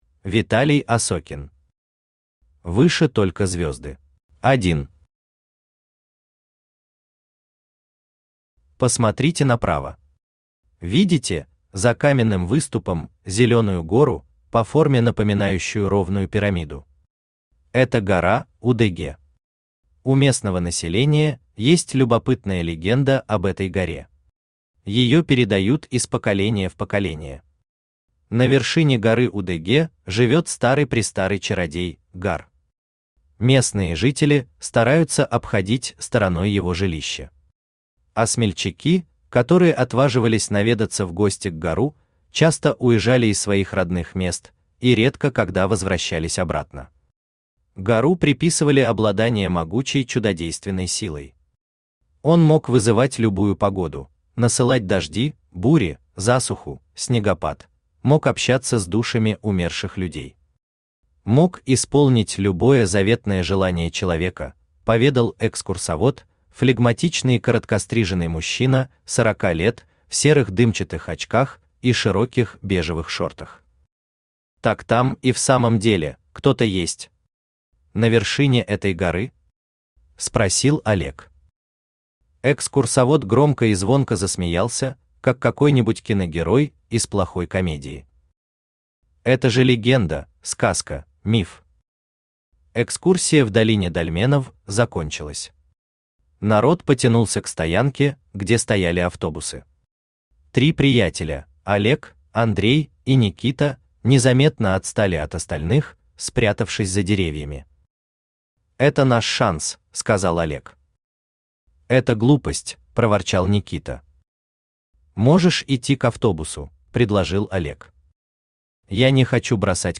Аудиокнига Выше только звёзды | Библиотека аудиокниг
Aудиокнига Выше только звёзды Автор Виталий Осокин Читает аудиокнигу Авточтец ЛитРес.